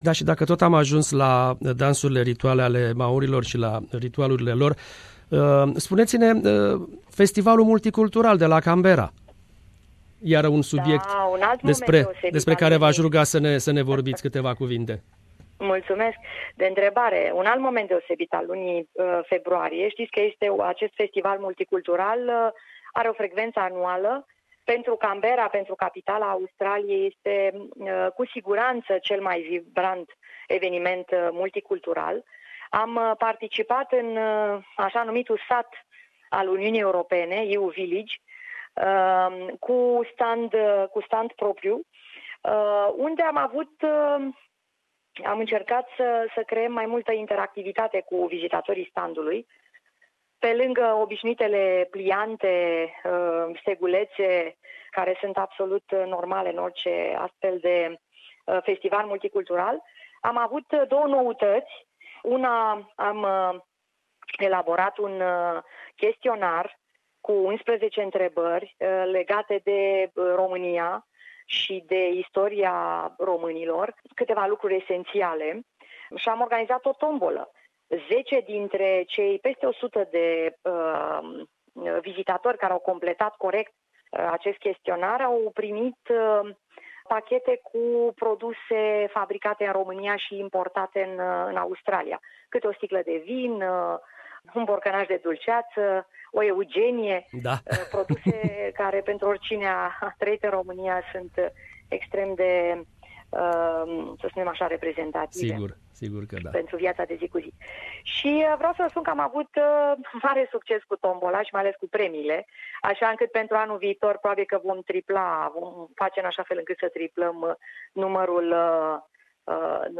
Interviu cu Excelenta Sa, Doamna Nineta Barbulescu, Ambasadorul Romaniei in Australia si Noua Zeelanda